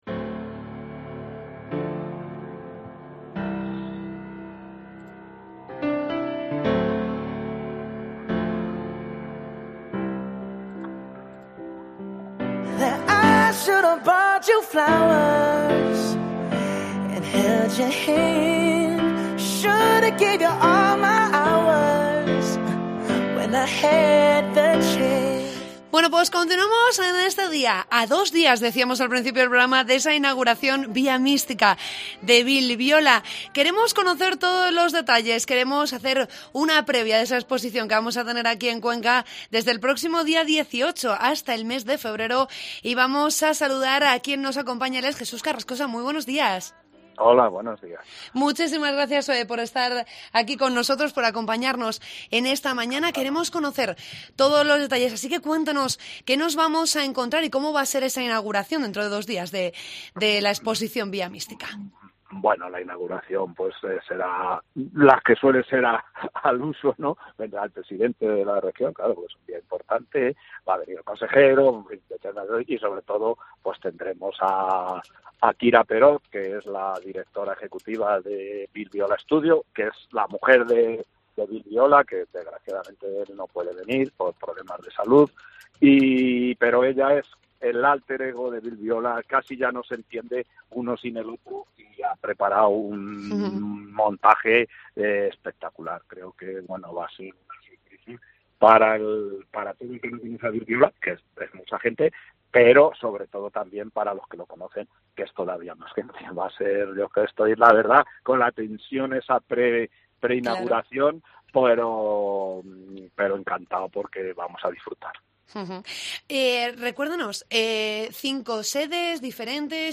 Nos lo cuenta el viceconsejero de Cultural, Jesús Carrascosa.